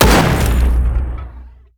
AntiMaterialRifle_1p_01.wav